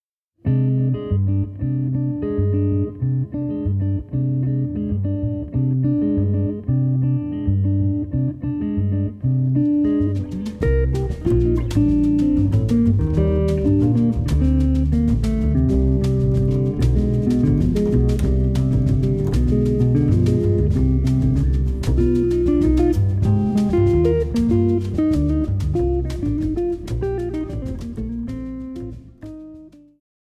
Guitar
Double-bass
Drums